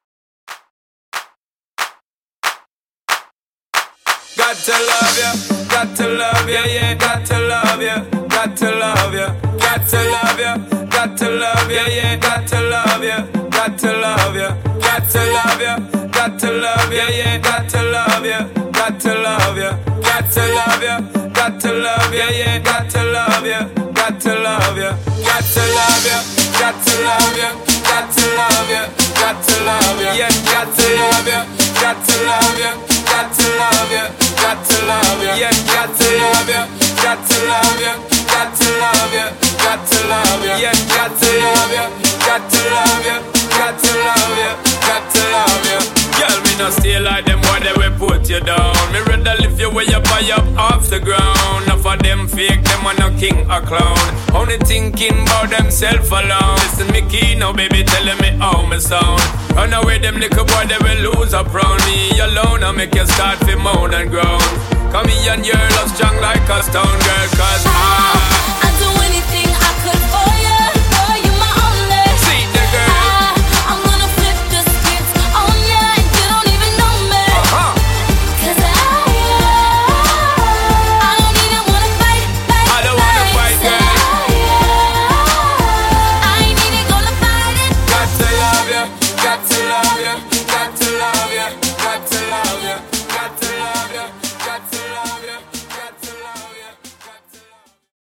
Clap Vocal Intros)Date Added